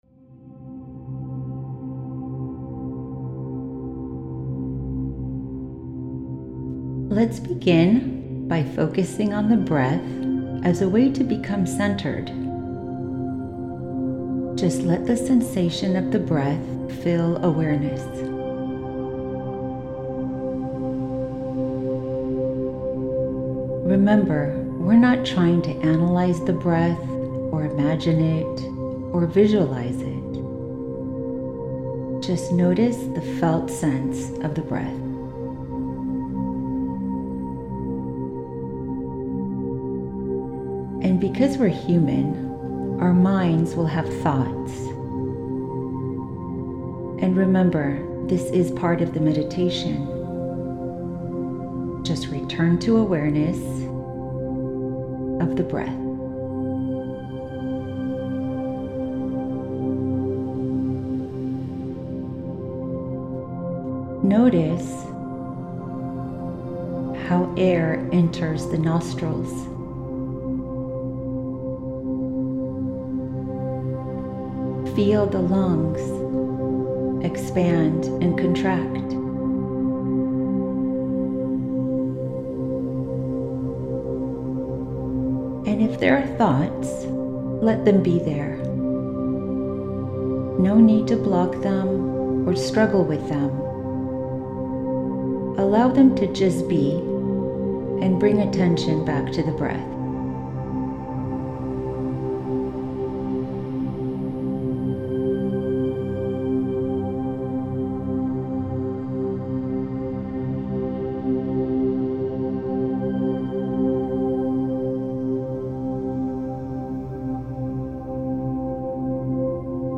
Transformational Awareness Meditation
This guided meditation is inspired by Dr. Dan Siegel’s Wheel of Awareness practice and integrates powerful elements to help you ground your attention, open your awareness, and cultivate emotional well-being. It combines neuroscience-based tools with heart-centered practices to shift you from a state of survival into a state of elevated creation.